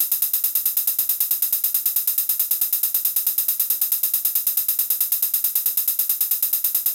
16TH CL HH-R.wav